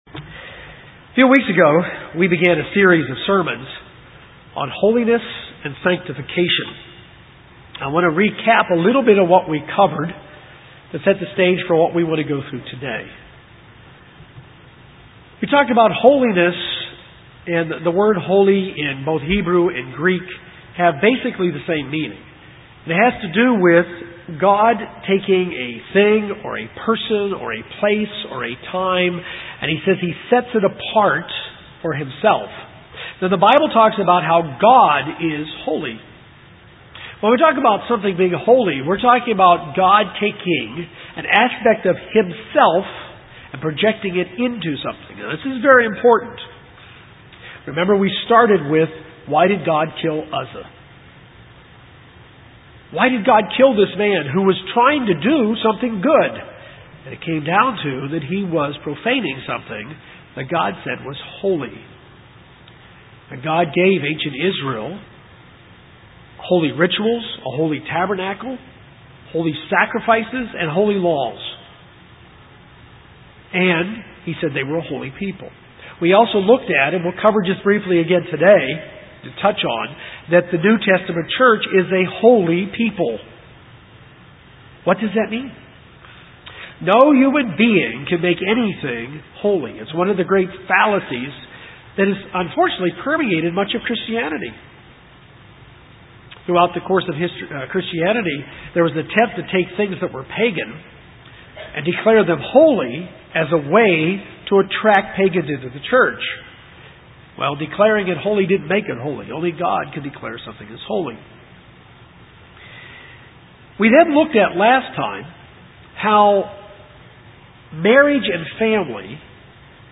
In this message, we continue to learn how marriage and family are holy.